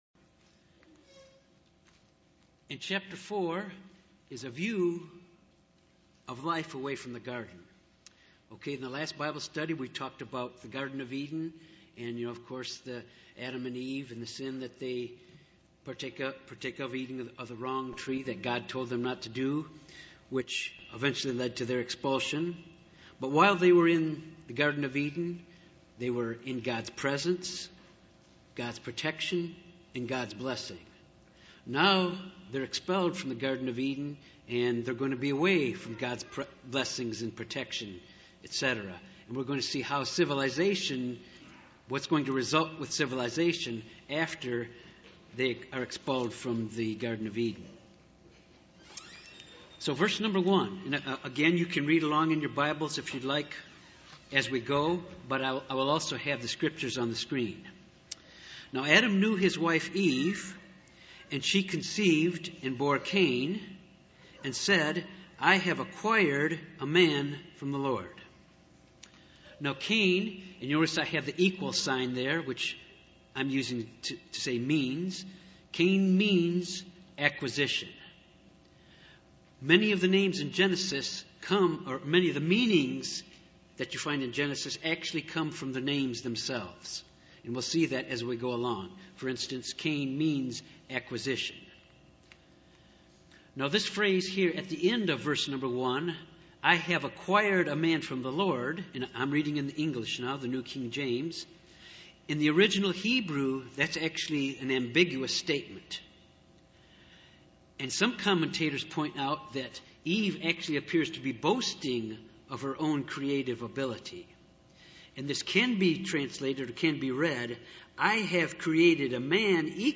This Bible study focuses on Genesis 4-6. Cain and Able born to Adam and Eve. Cain slays Abel.